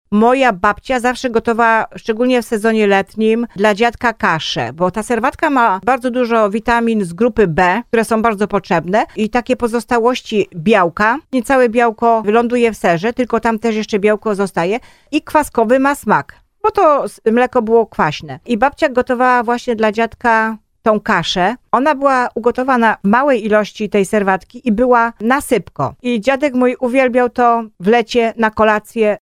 Jedna z członkiń KGW z Otfinowa wspomina, że w procesie robienia sera na krowim mleku powstała serwatka, której jej babcia nigdy nie wylewała.
5serwatka.mp3